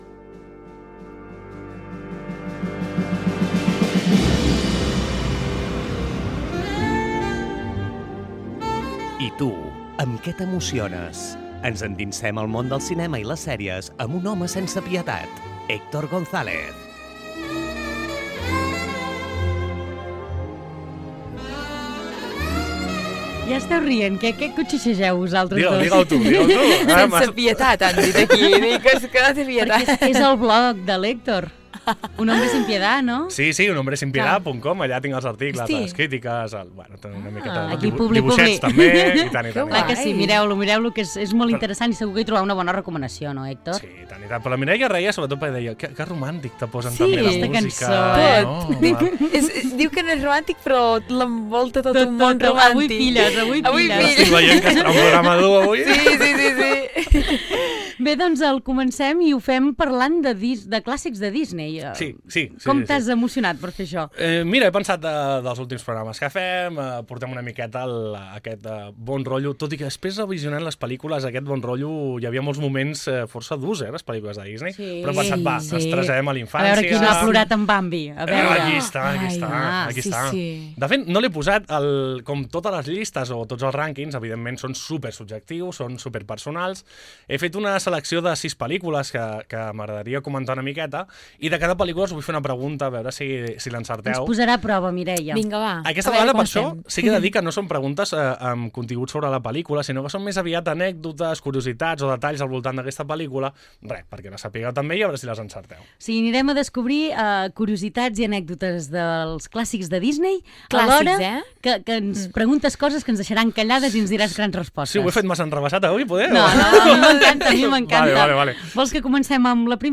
He escogido seis películas que más me han marcado de pequeño de las películas Disney y he buscado curiosidades poco conocidas sobre ellas para poder elaborar un test y poder jugar con mis compañeras de programa.